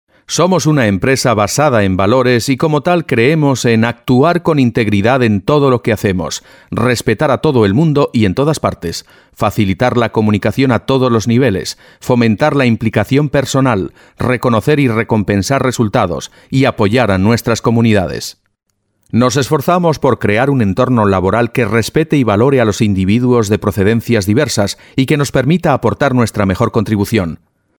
Spanish – male – AK Studio